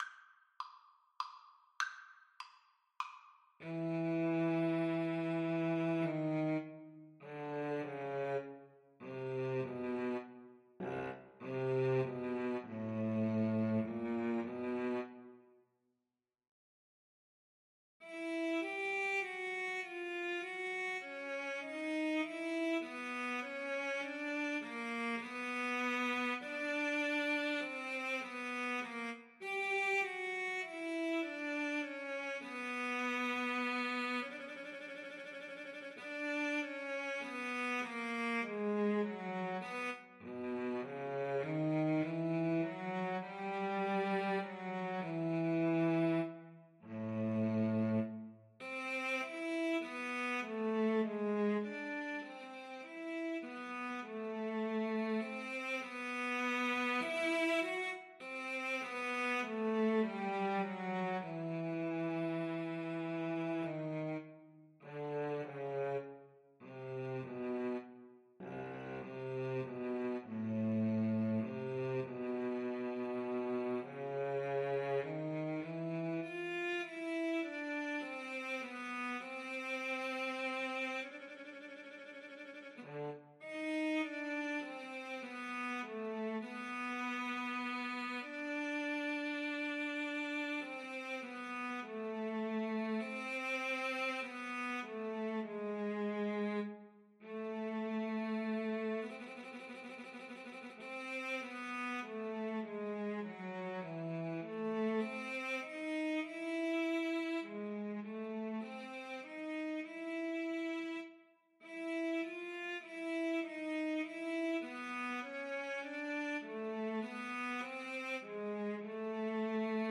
Lamento
3/4 (View more 3/4 Music)
Cello Duet  (View more Intermediate Cello Duet Music)
Classical (View more Classical Cello Duet Music)